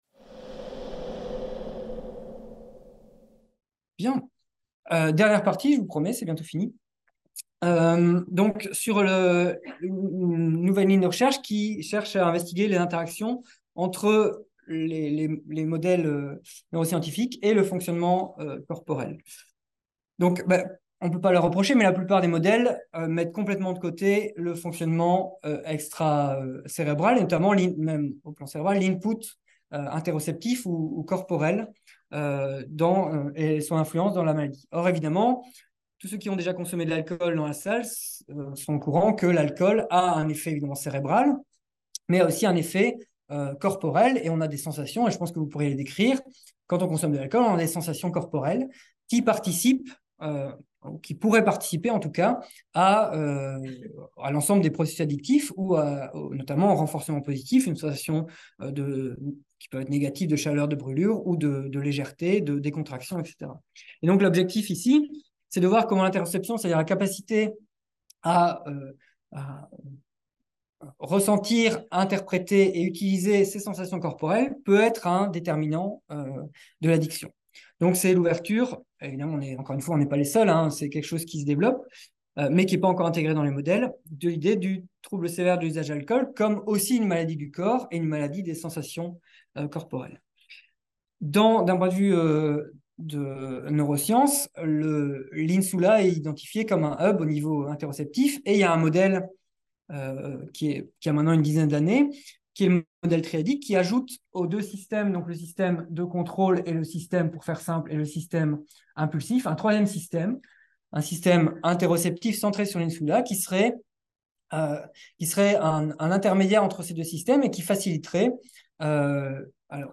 Ce séminaire s’adresse à tous les étudiants de master de psychologie de l’UFR, quelque soit leur parcours de master.